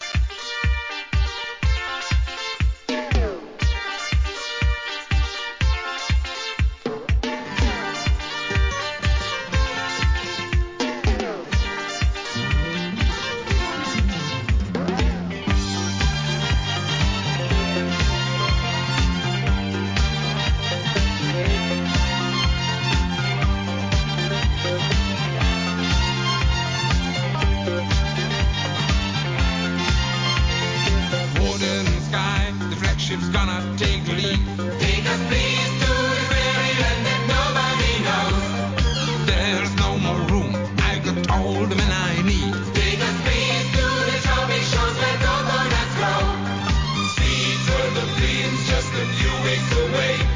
¥ 385 税込 関連カテゴリ SOUL/FUNK/etc...